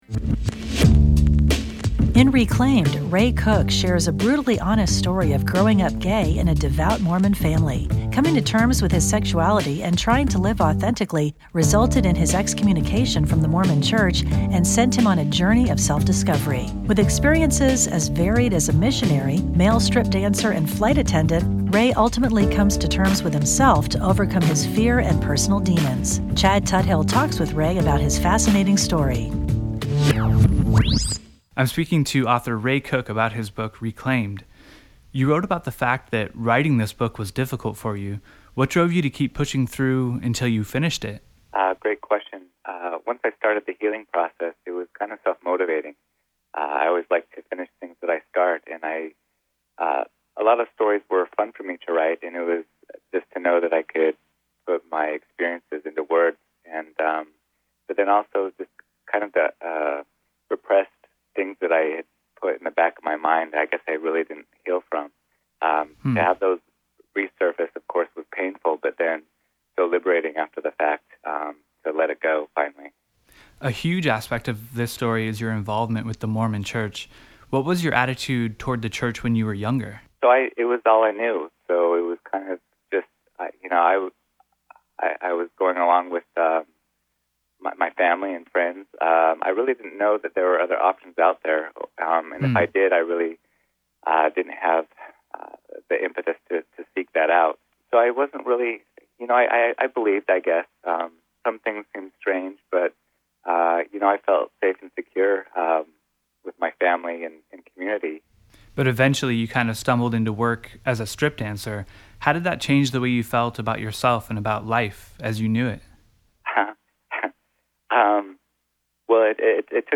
titles=Radio Interview